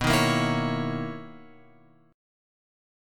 B Major 11th
BM11 chord {7 6 8 9 x 9} chord